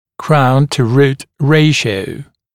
[kraun-tu-ruːt ‘reɪʃɪəu][краун-ту-ру:т ‘рэйшиоу]соотношение высоты коронки и длины корня